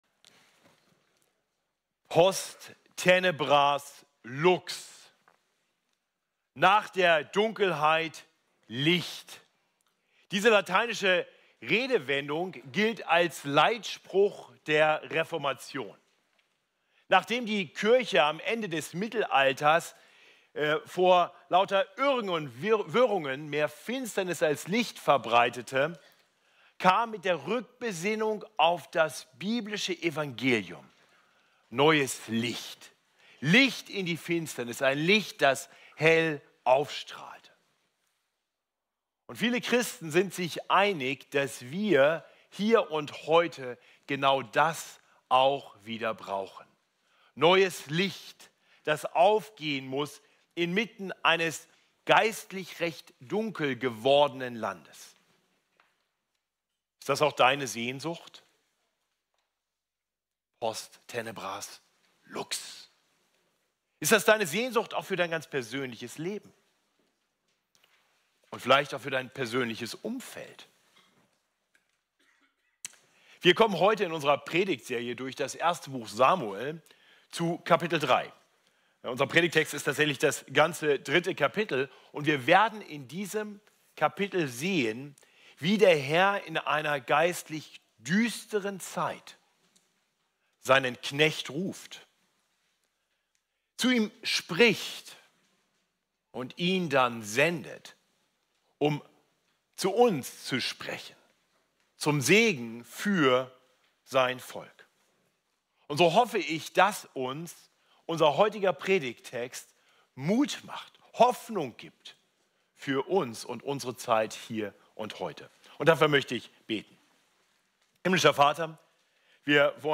FeG München Mitte Predigt Podcast